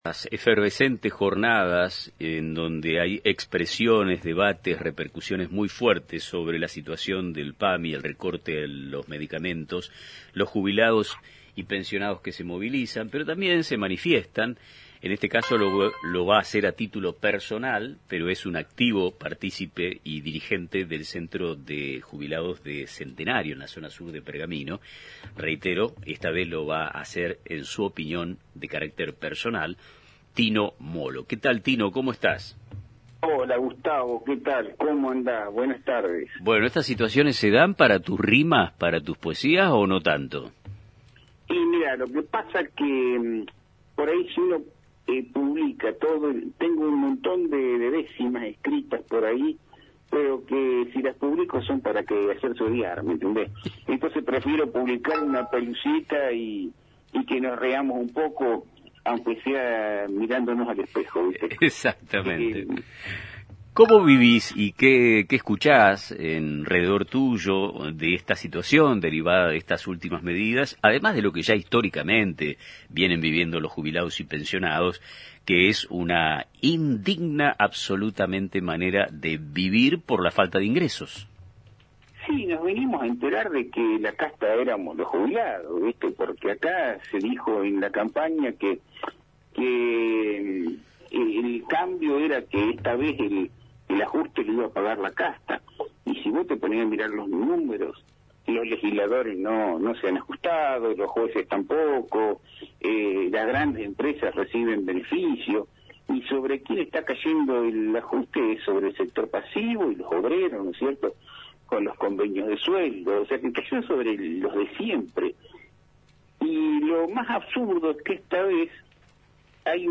En una reciente entrevista en el programa Nuestro Tiempo